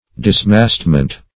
Search Result for " dismastment" : The Collaborative International Dictionary of English v.0.48: Dismastment \Dis*mast"ment\, n. The act of dismasting; the state of being dismasted.